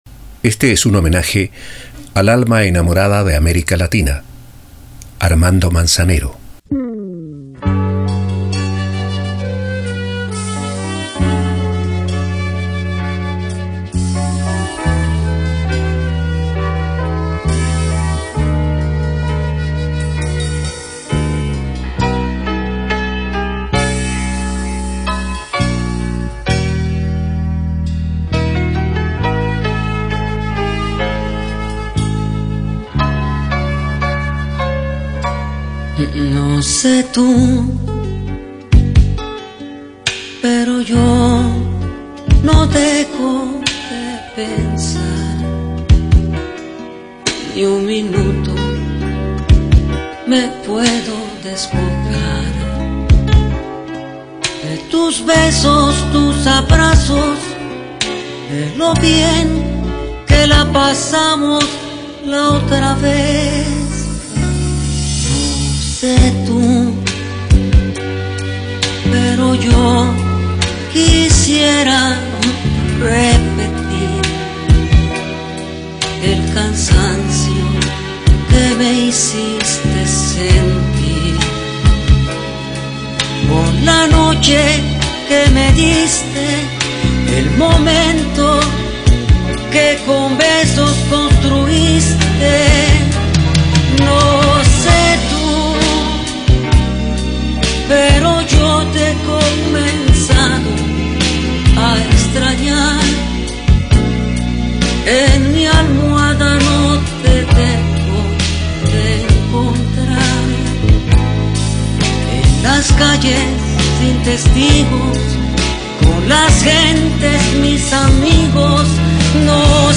Valioso reportaje radiofónico